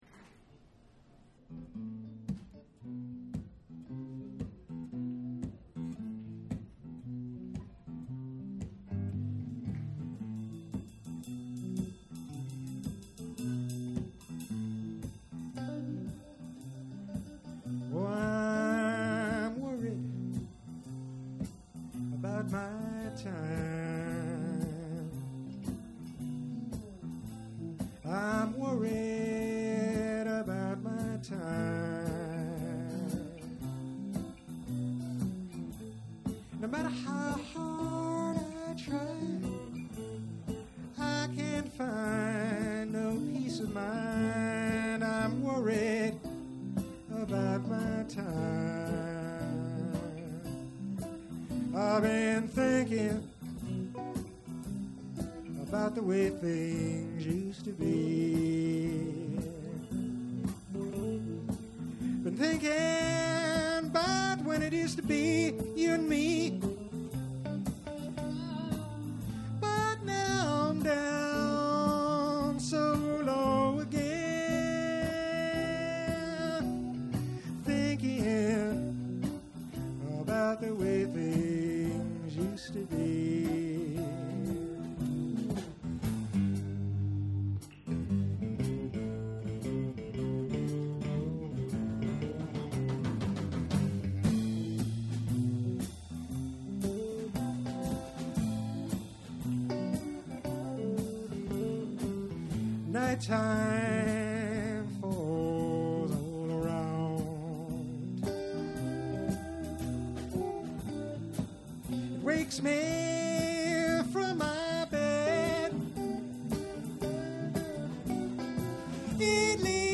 Vintage Live & Rehearsal Recordings